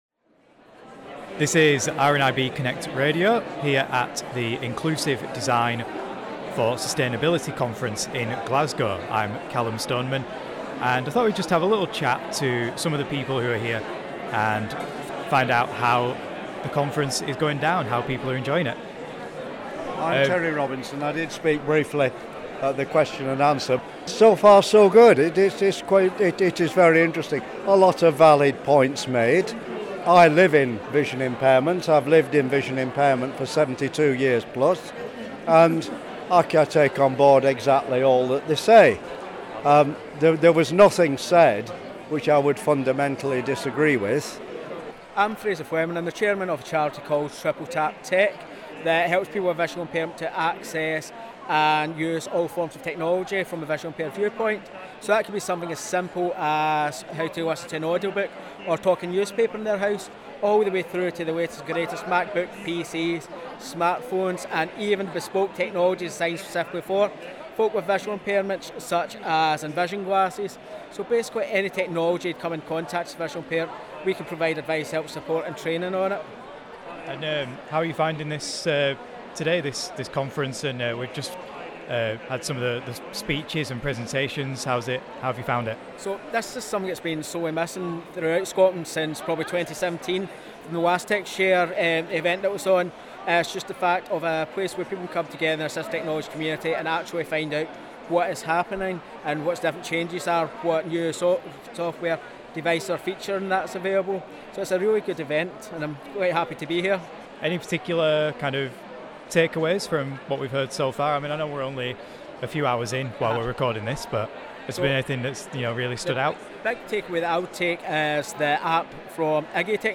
As the IDS conference got underway in Glasgow